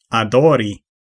Ääntäminen
Synonyymit dote Ääntäminen US Tuntematon aksentti: IPA : /əˈdɔː/ IPA : /əˈdɔːr/ Haettu sana löytyi näillä lähdekielillä: englanti Käännös Ääninäyte Verbit 1. adori 2. ŝategi Määritelmät Verbit To worship .